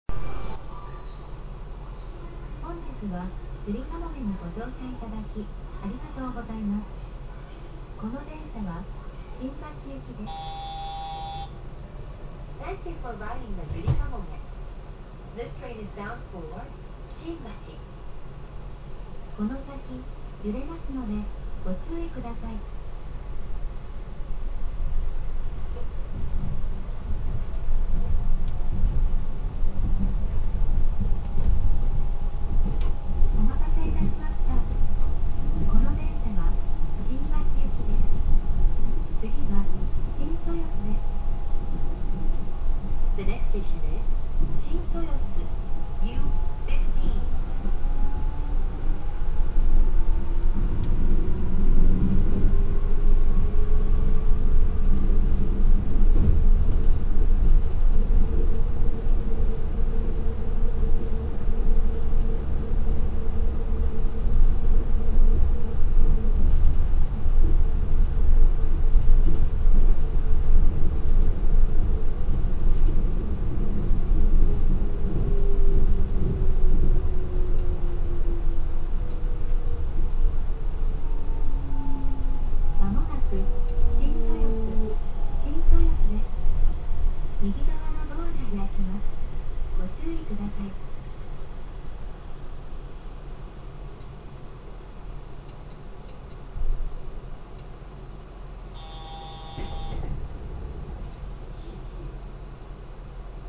〜車両の音〜
・7500系走行音
【ゆりかもめ】豊洲→新豊洲
7300系と同様の三菱IGBTとなります。
toyosu-shintoyosu.mp3